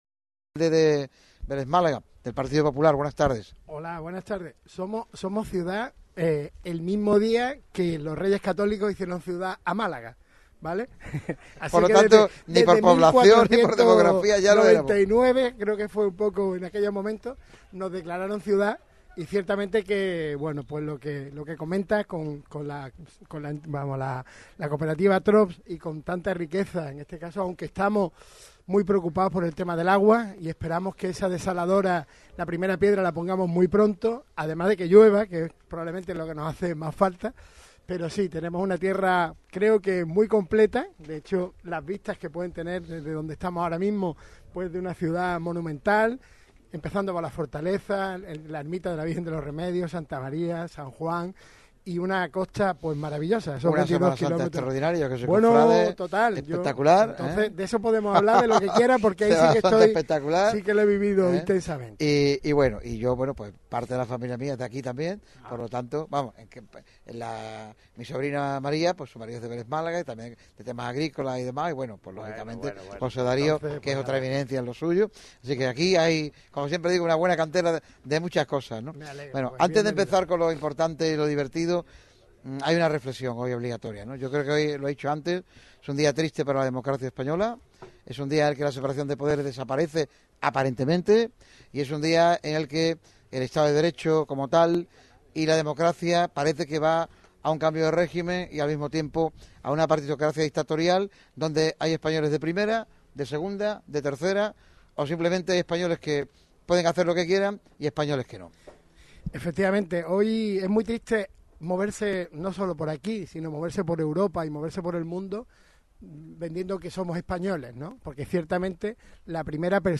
Jesús Lupiáñez (PP), alcalde de Vélez-Málaga, ha sido el primer protagonista que ha pasado por las instalaciones del Vivar Téllez durante el programa especial que ha realizado hoy el equipo de Radio MARCA Málaga desde la capital de la Axarquía. Para Lupiáñez, solucionar el problema de la sequía es uno de los objetivo de la legislatura.
ENT-ALCALDE-VELEZ-MALAGA.mp3